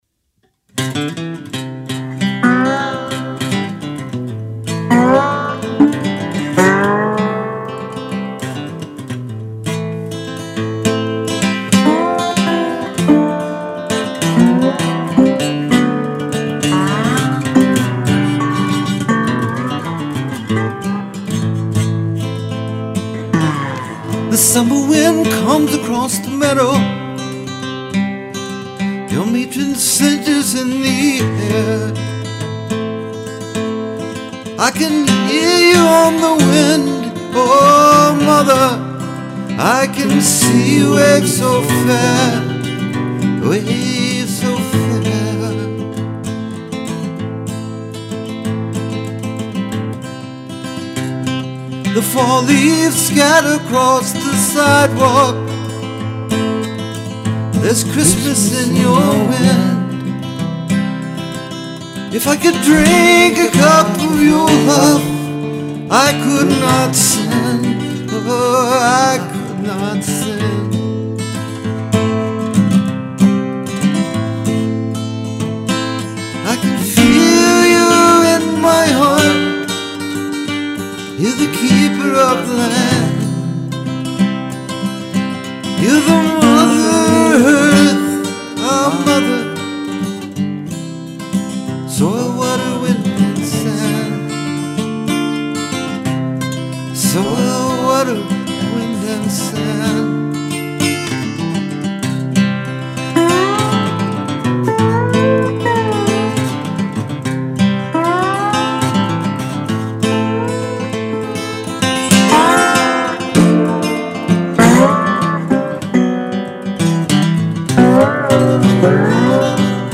The lead vocal and acoustic guitar were played live on one track. There is one recorded dobro track, copied then doubled with a harmonizer and panned right and left. The harmony vocal is panned slightly left.
“Our Mother” Alternate mix than the SoundCloud posting: